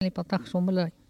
Localisation Saint-Jean-de-Monts
Langue Maraîchin
Patois - archives
Catégorie Locution